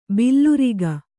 ♪ billuriga